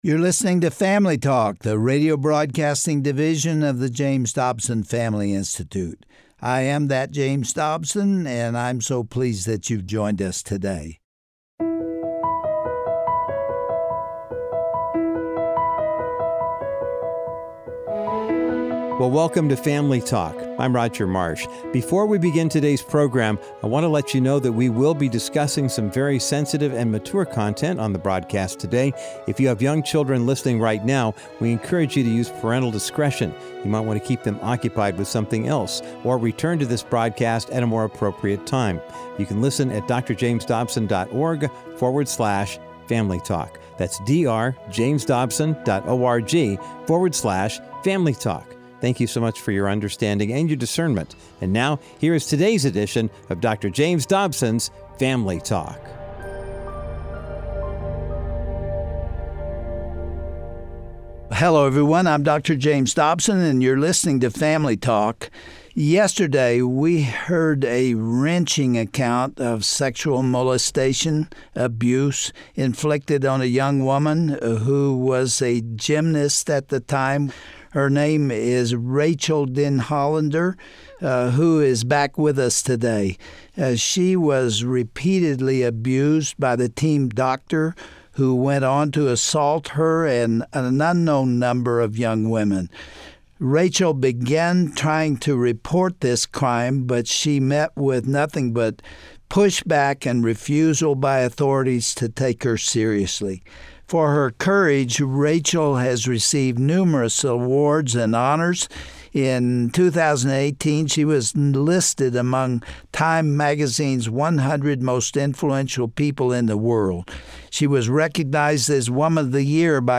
On today’s edition of Family Talk, Dr. James Dobson concludes his conversation with Rachael Denhollander about her ongoing mission to protect survivors of abuse.